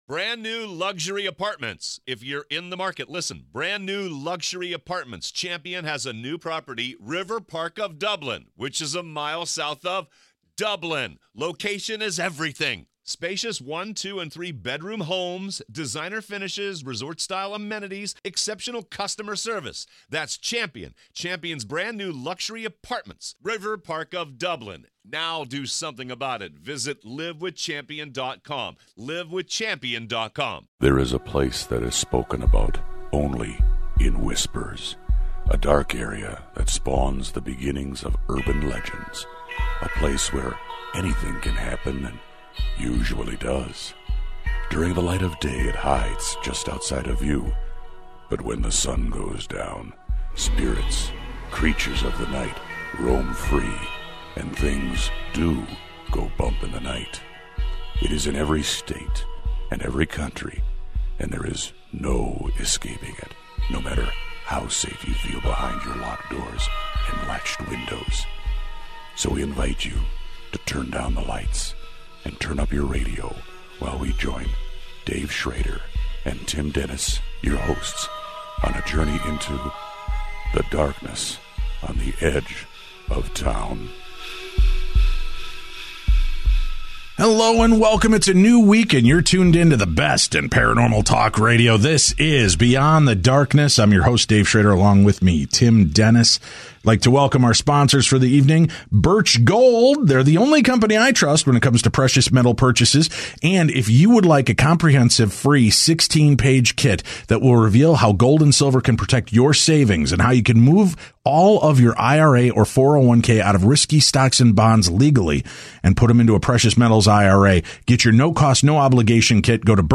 It's a new week and you're tuned into the best and paranormal talk radio.